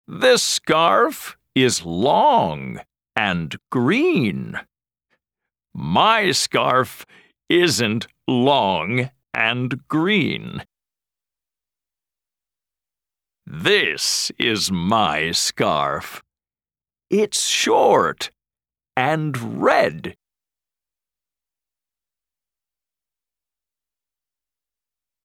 Track 3 Where's My Hat US English.mp3